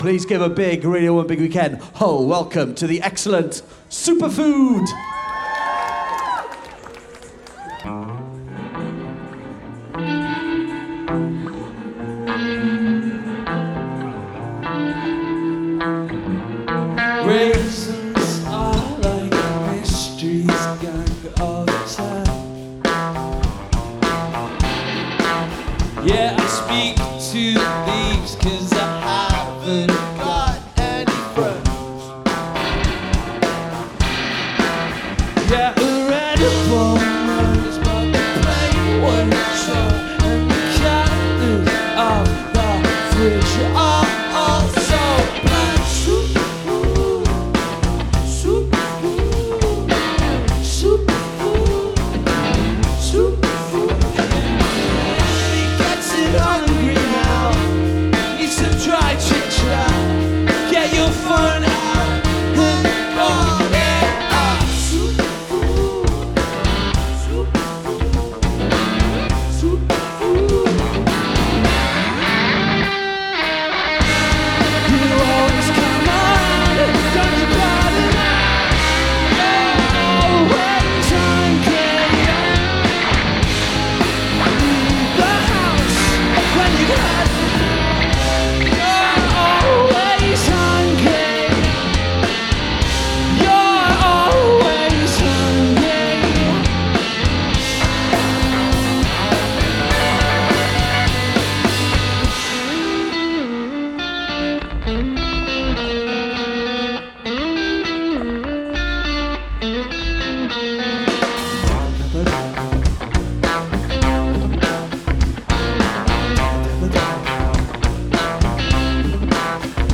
Live from the BBC Introducing Stage on May 27, 2017.
vocals, guitar
bass
drums
pop ensemble